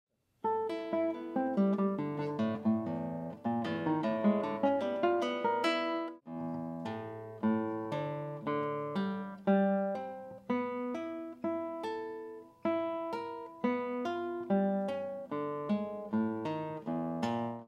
94. ligados com corda solta.m4v